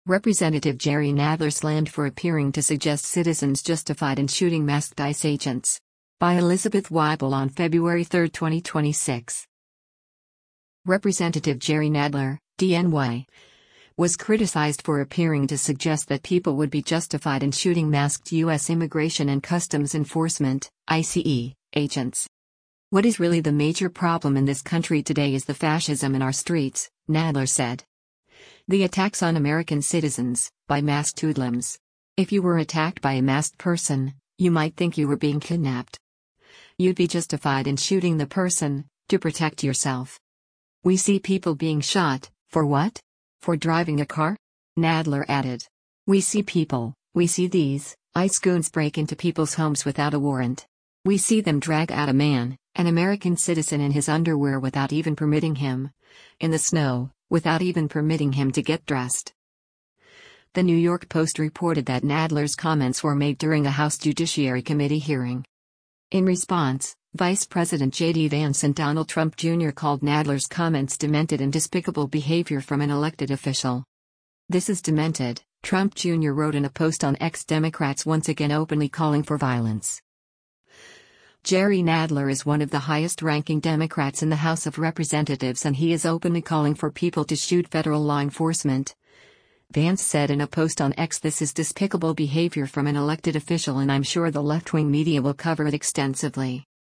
The New York Post reported that Nadler’s comments were made during a House Judiciary Committee hearing.